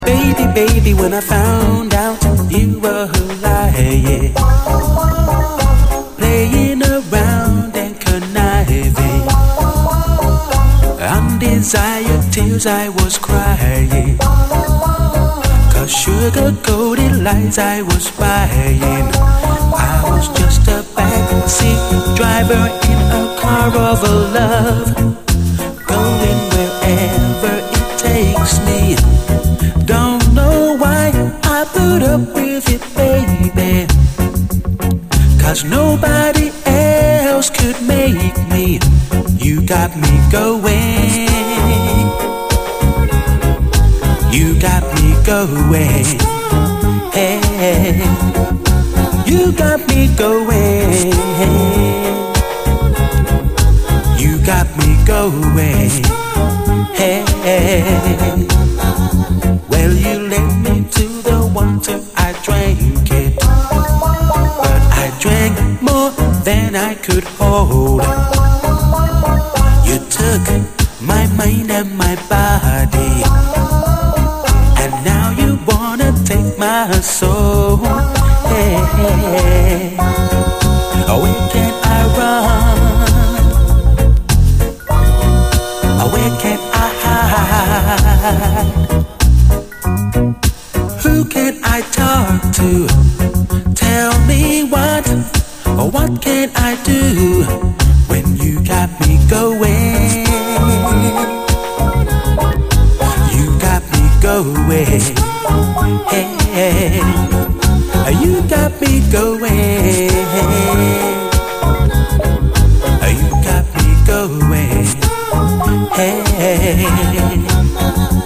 REGGAE
スカスカのプロダクションが可愛らしいナイス・カヴァー！